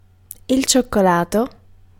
Ääntäminen
Belgique (Bruxelles): IPA: [lə ʃo.ko.lɑ]